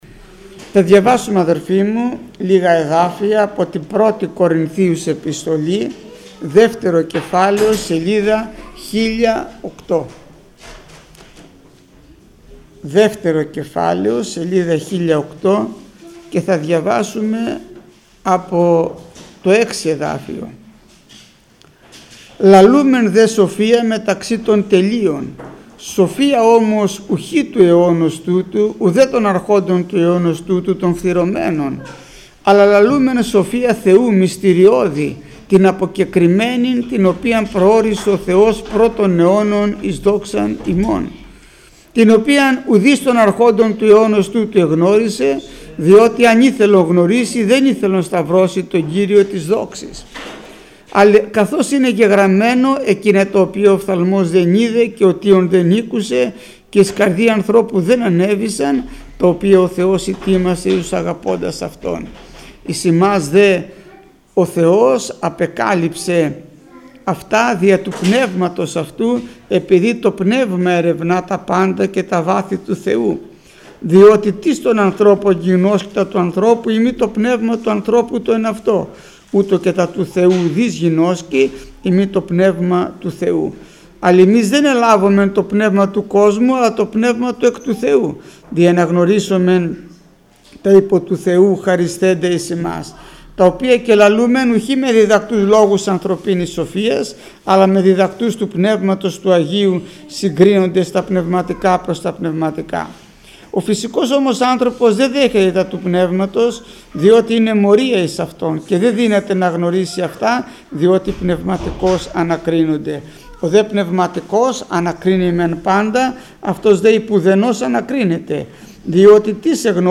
Μηνύματα Θείας Κοινωνίας